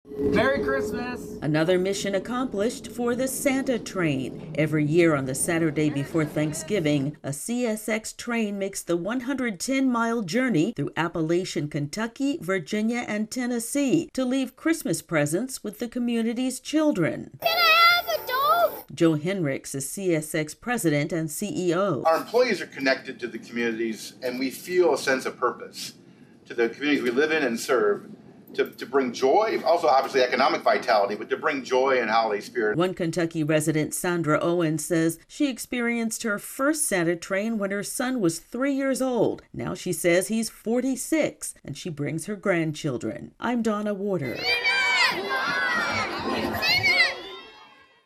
Another mission accomplished for the Santa Train. AP correspondent